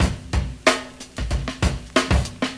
• 93 Bpm Breakbeat D Key.wav
Free drum loop sample - kick tuned to the D note.
93-bpm-breakbeat-d-key-X7A.wav